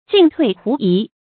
進退狐疑 注音： ㄐㄧㄣˋ ㄊㄨㄟˋ ㄏㄨˊ ㄧˊ 讀音讀法： 意思解釋： 進退兩難，遲疑不決。